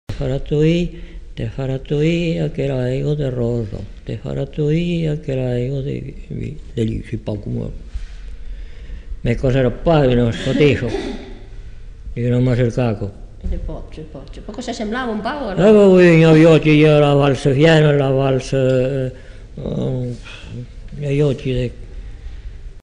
Répertoire d'airs à danser sifflés ou joués au saxophone
enquêtes sonores